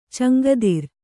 ♪ caŋgadir